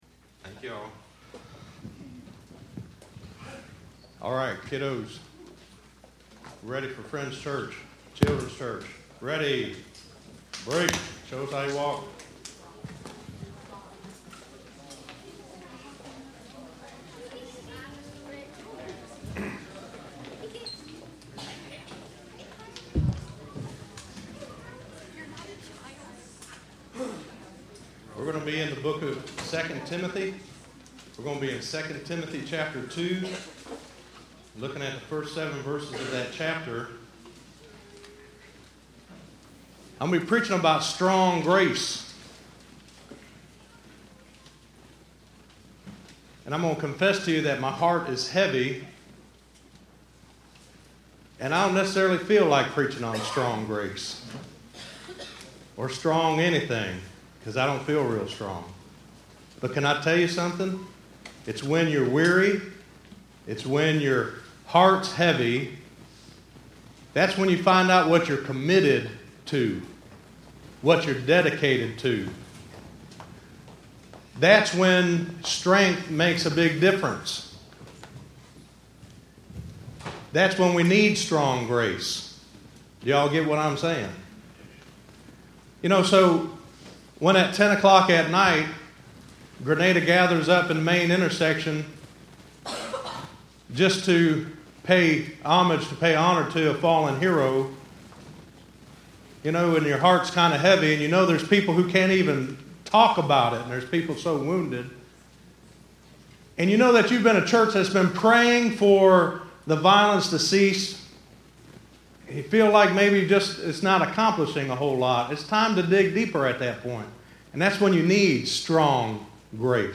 Listen to Strong In Grace - 02_21_16_Sermon.mp3